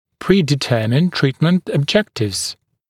[ˌpriːdɪ’tɜːmɪnd ‘triːtmənt əb’ʤektɪvz] [ɔb-][ˌпри:ди’тё:минд ‘три:тмэнт эб’джэктивз] [об-]заранее определенные цели лечения